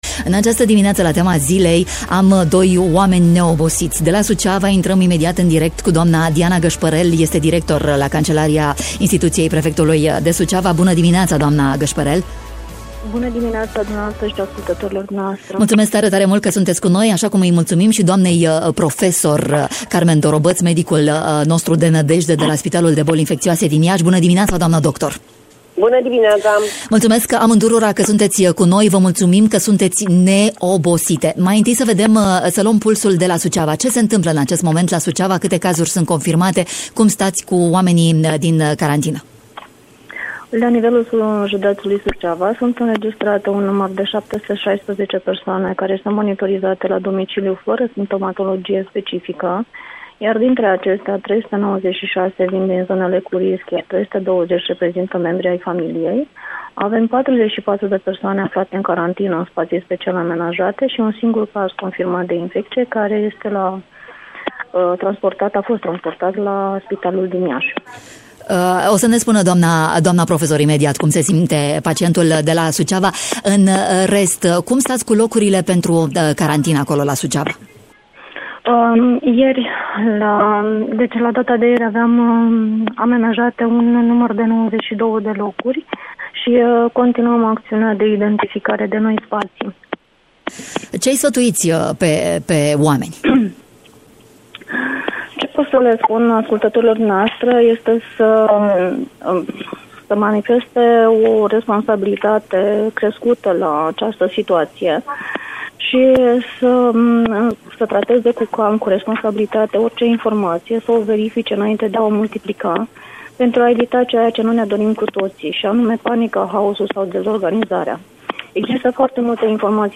Situația la zi în Moldova! Am luat pulsul acestei încercări prin care trece umanitatea în matinalul Radio Romania Iași!